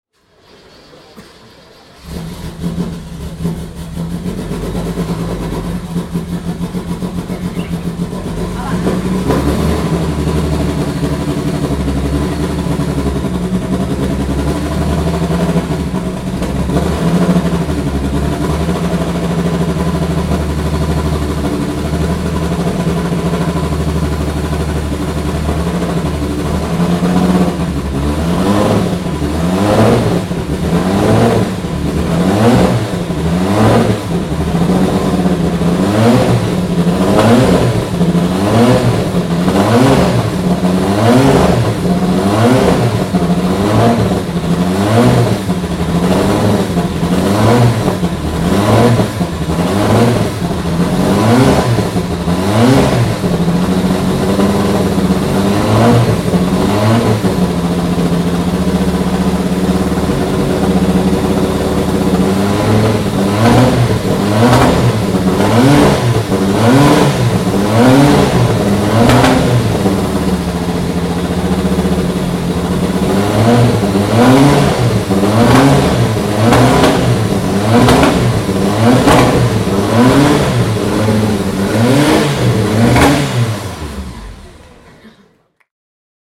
Porsche 935 "Moby Dick" (1978) - Starten und Hochdrehen an der Porsche Sound-Nacht 2013